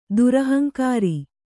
♪ durahaŋkāri